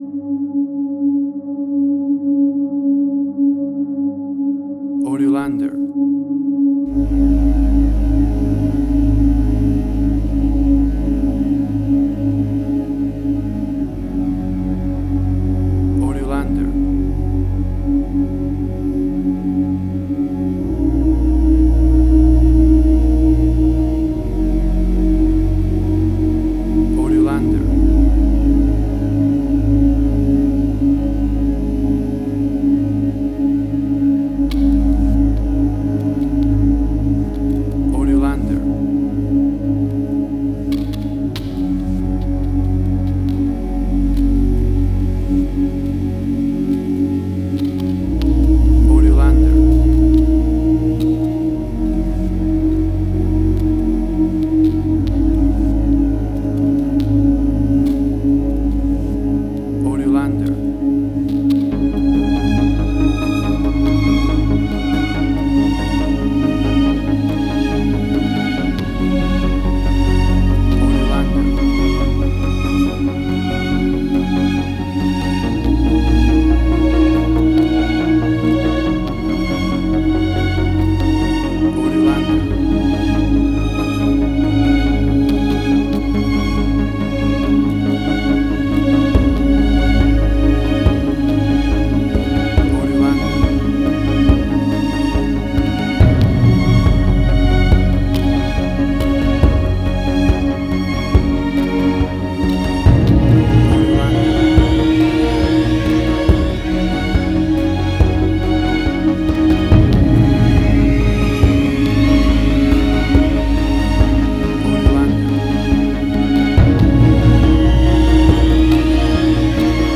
Cinematic Industrial Sci-fi
Tempo (BPM): 70